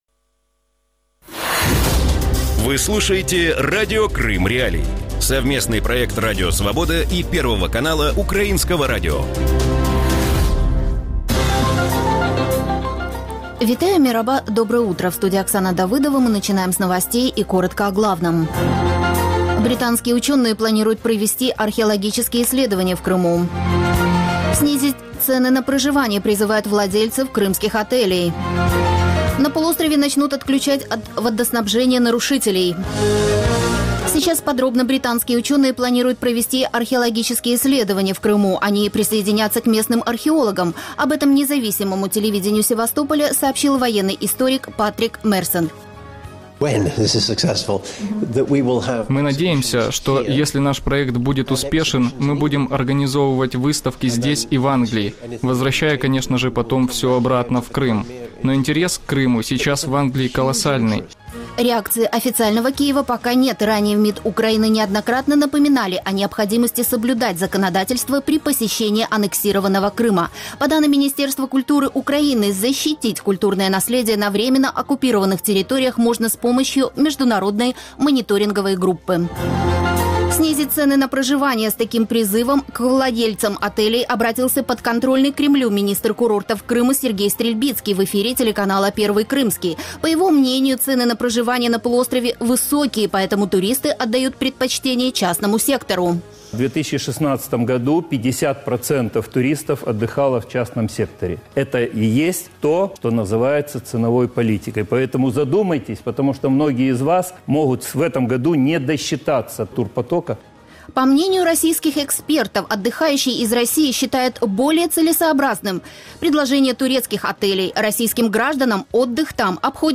Утренний выпуск новостей о событиях в Крыму. Все самое важное, что случилось к этому часу на полуострове.